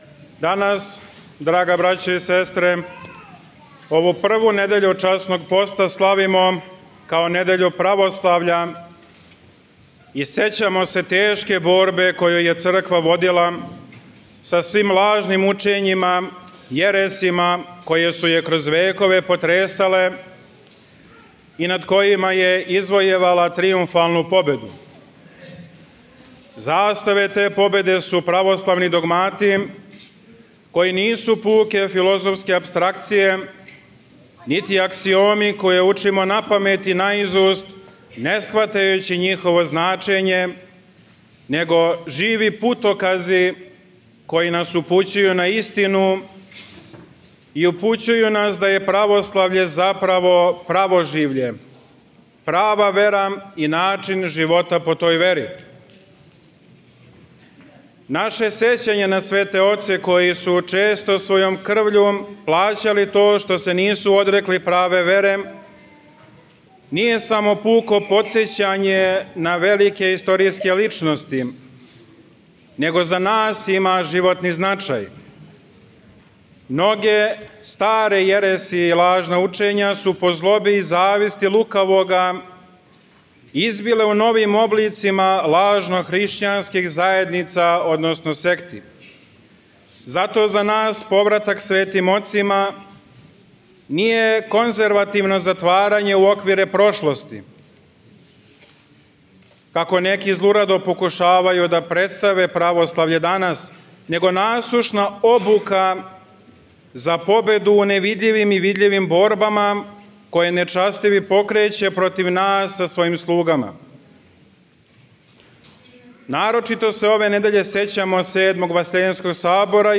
Звучни запис беседе